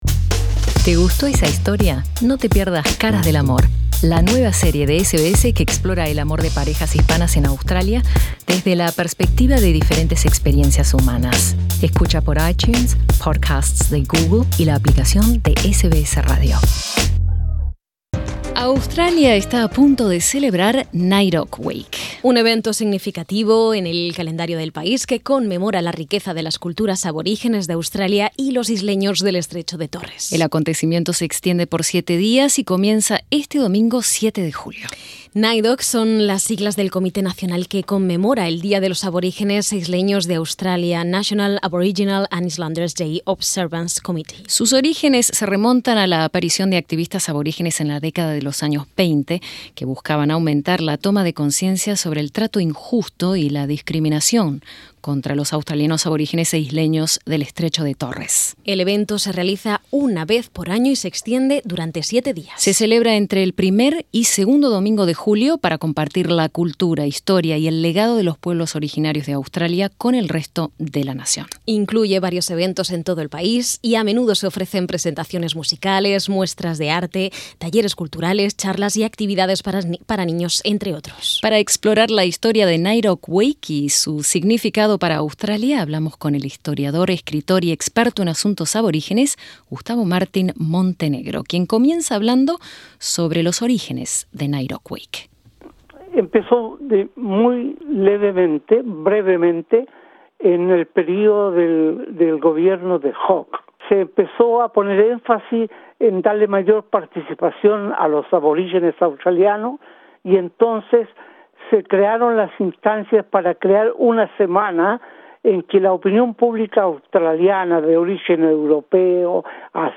Escucha la conversación sobre Naidoc Week con el historiador